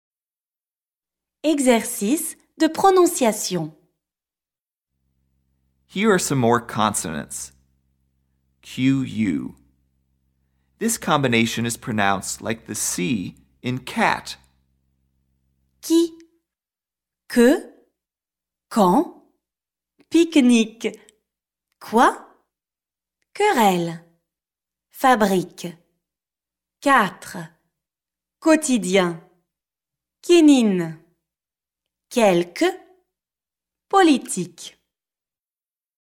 PRONONCIATION
qu – This combination is pronounced like the “c” in “cat.”